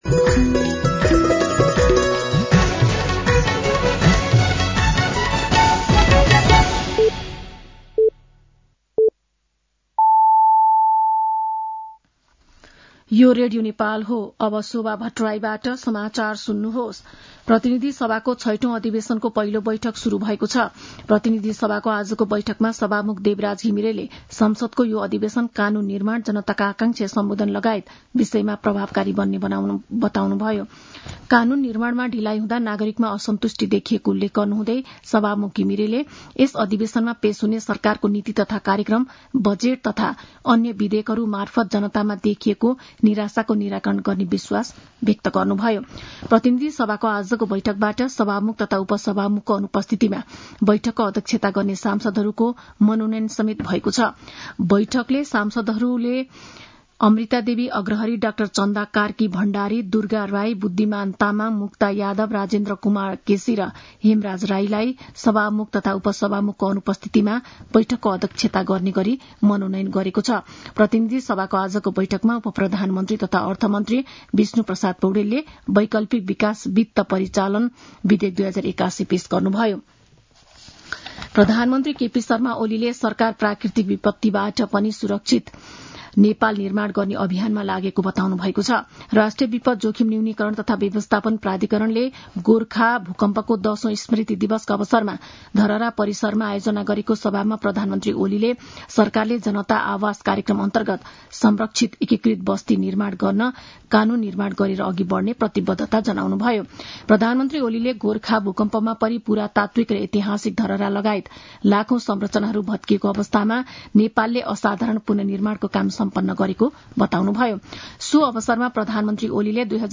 साँझ ५ बजेको नेपाली समाचार : १२ वैशाख , २०८२